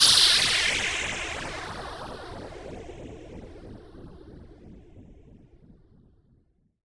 Drop_FX_2_C3.wav